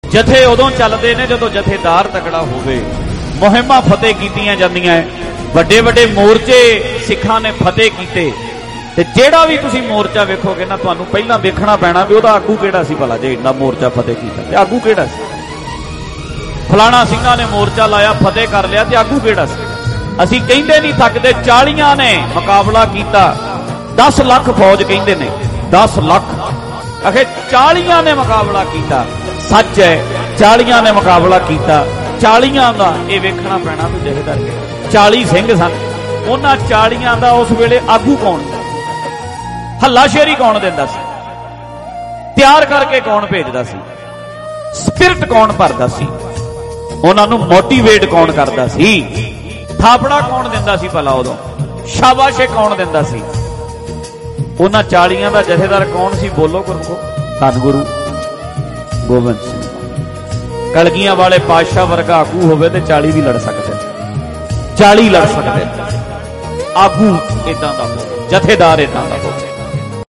11 Apr 2020 Live Diwan Bhai Ranjit Singh from Parmeshar Dwar Sahib Patiala | DhadrianWale Diwan Audios mp3 downloads gurbani songs